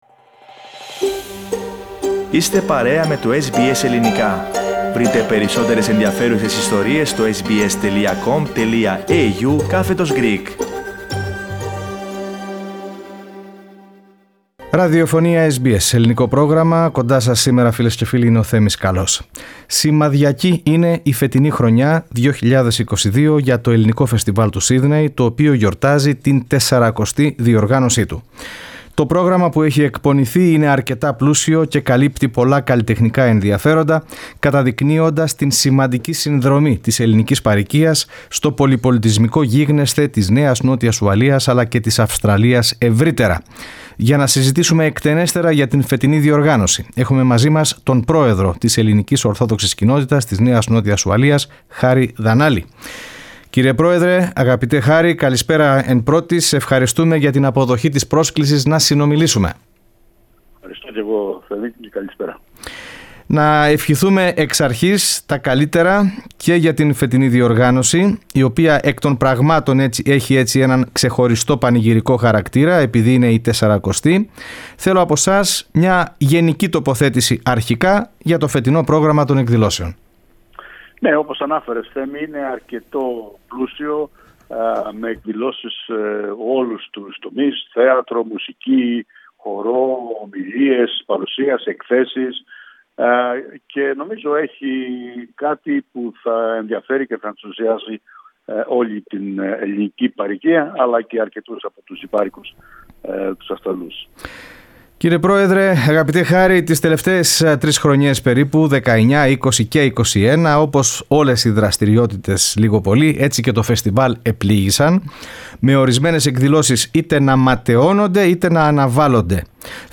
*Σημείωση: Η συνέντευξη μεταδόθηκε ζωντανά την Πέμπτη 3 Μαρτίου, όταν ακόμη δεν είχε ανακοινωθεί η αναβολή της γιορτής στο Darling Harbour λόγω των πλημμυρικών φαινομένων.